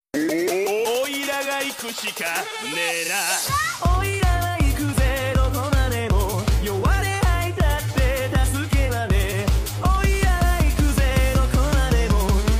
oiragaikushikaneena Meme Sound Effect